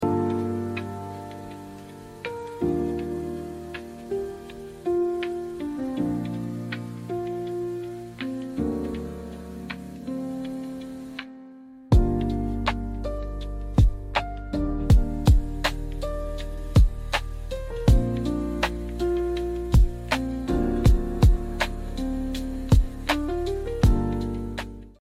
Lo-fi